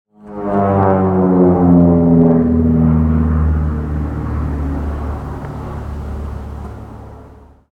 Vintage Airplane Engine Passing In The Distance Sound Effect
Description: Vintage airplane engine passing in the distance sound effect. Hear the characteristic engine sound of an old propeller airplane flying in the distance.
Use it to create an authentic atmosphere with the classic sound of a retro airplane engine passing far away.
Vintage-airplane-engine-passing-in-the-distance-sound-effect.mp3